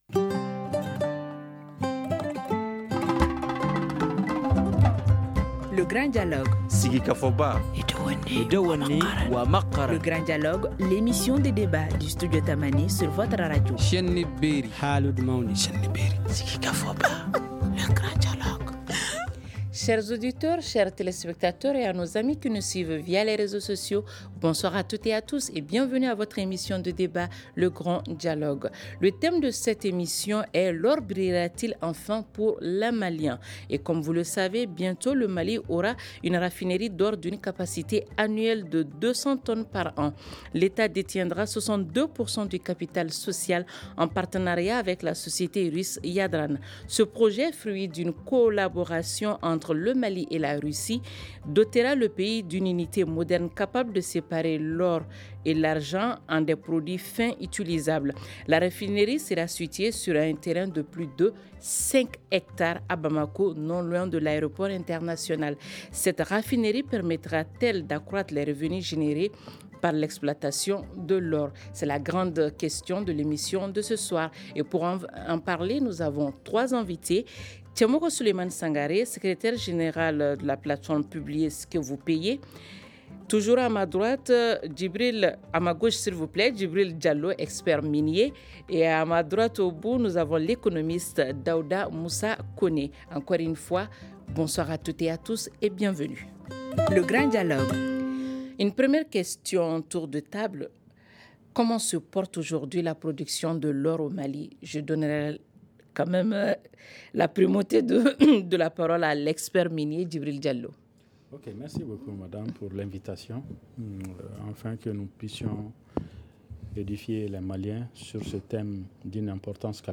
Expert minier
Economiste